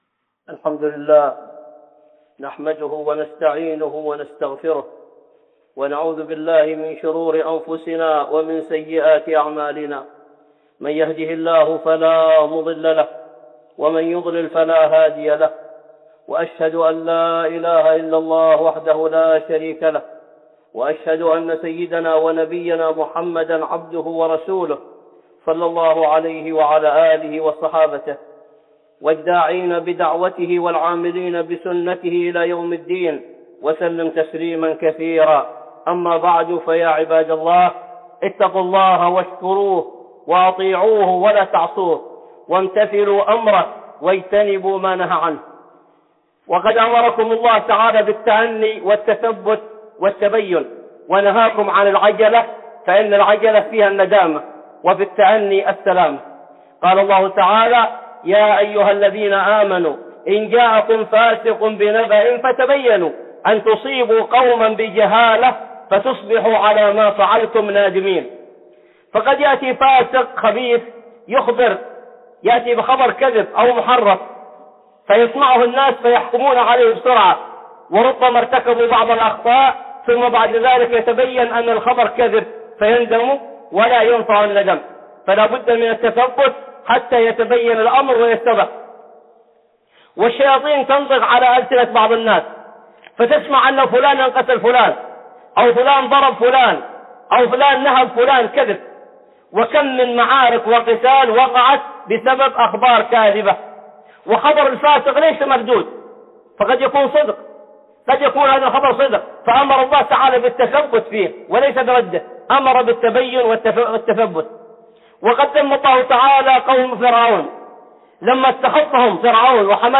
(خطبة جمعة) في التأني السلامة وفي العجلة الندامة